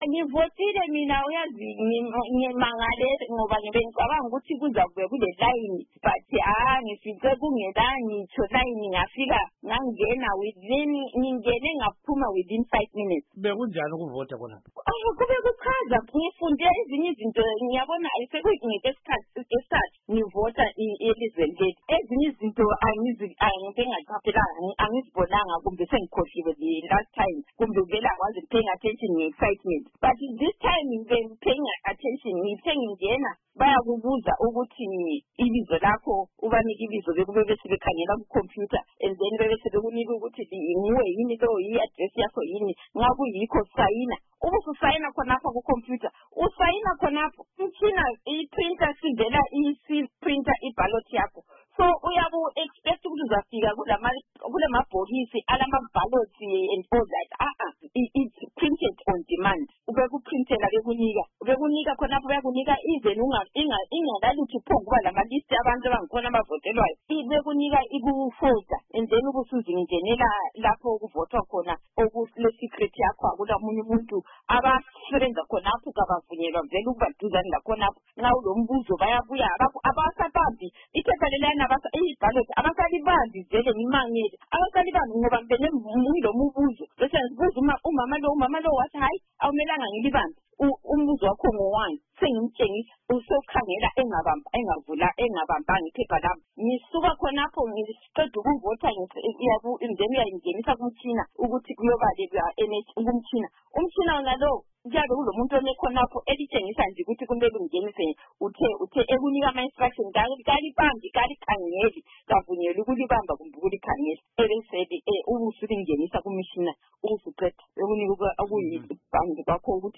Ingxoxo Esiyenze